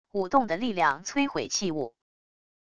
舞动的力量摧毁器物wav音频